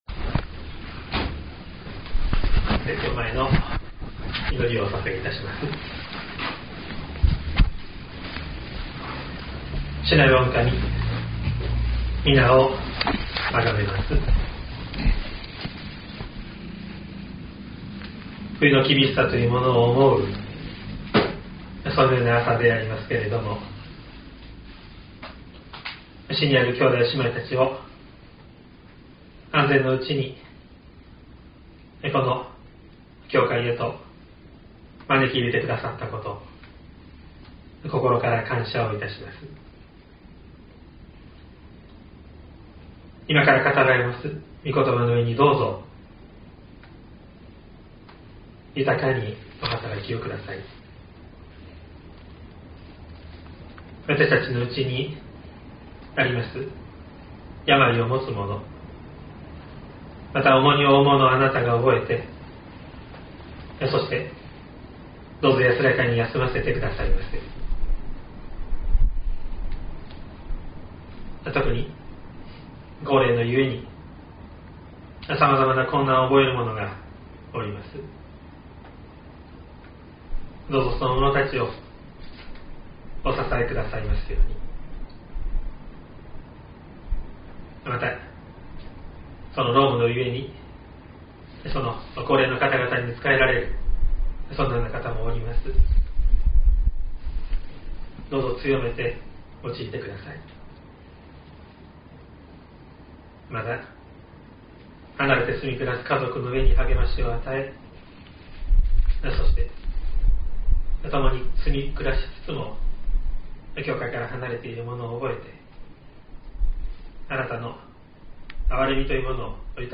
2023年01月22日朝の礼拝「しるしとして定められた御子イエス」西谷教会
説教アーカイブ。
音声ファイル 礼拝説教を録音した音声ファイルを公開しています。